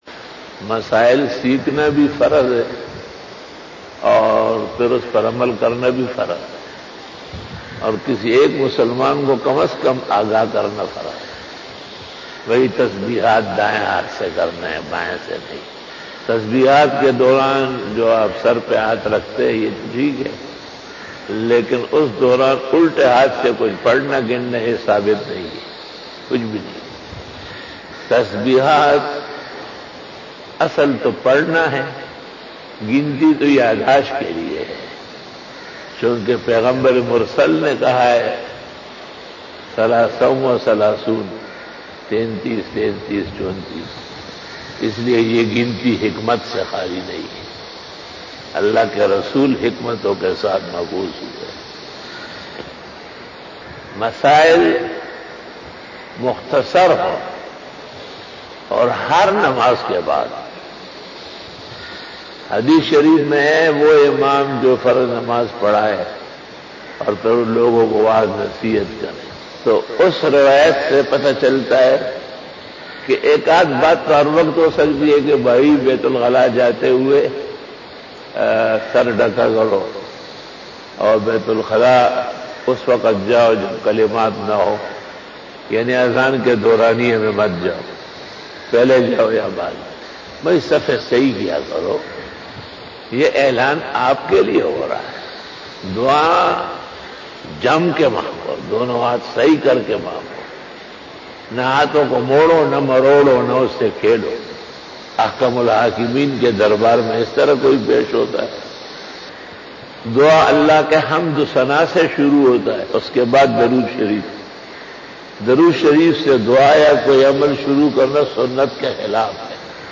Asar bayan 12 September 2020 (23 Muharram 1442HJ) Saturday
After Namaz Bayan